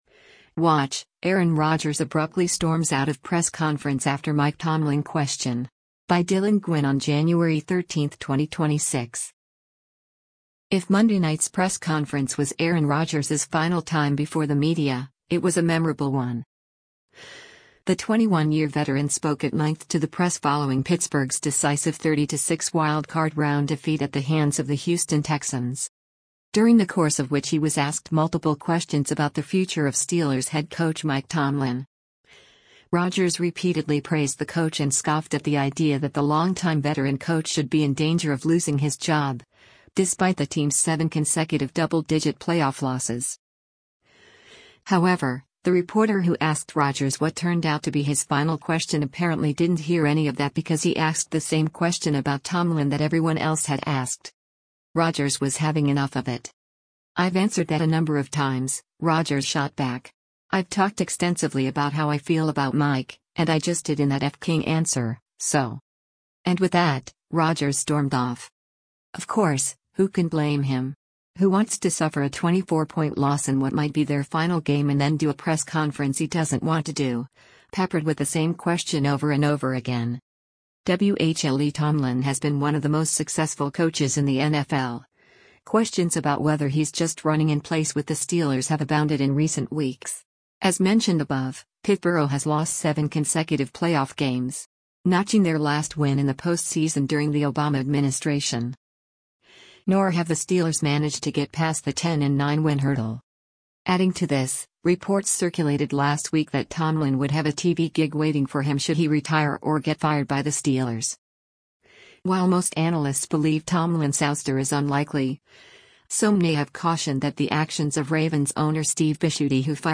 If Monday night’s press conference was Aaron Rodgers’s final time before the media, it was a memorable one.
“I’ve answered that a number of times,” Rodgers shot back.